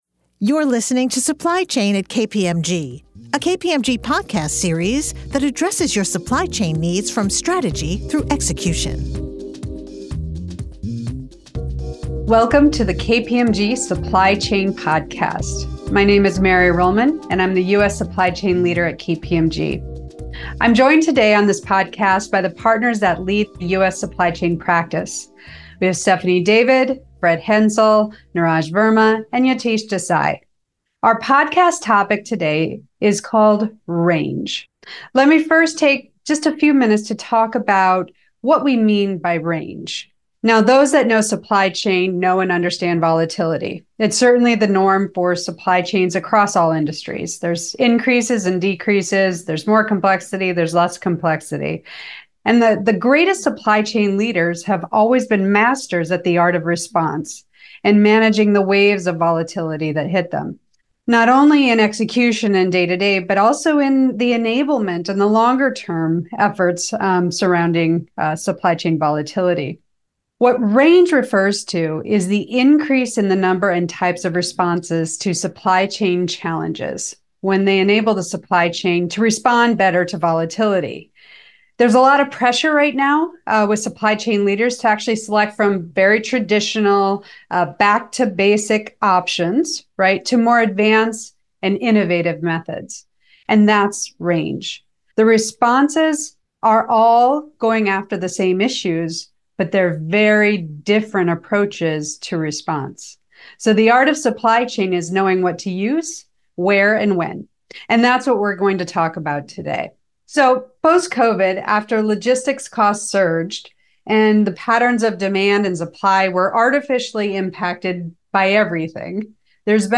Hear from KPMG Supply Chain partners as they discuss the market dynamics that are impacting their clients today.